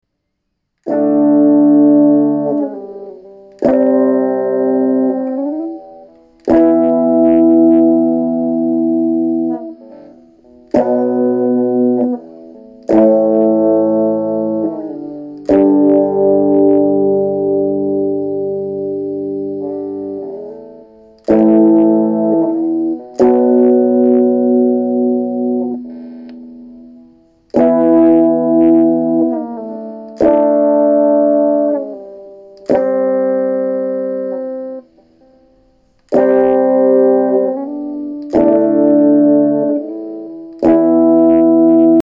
Eben gespielt natürlich auf Anfängerniveau und die Aufnahme ist auch nicht gut, aber man hört glaub gut raus wie sich das Ganze anhört.
Der Amp ist überall auf neutral oder ausgeschalten. Einfach Crunch-Mode und auf der Gitarre den hinderten Spot beim Hebel (müsste Humbucker sein). Tone alles auf 0.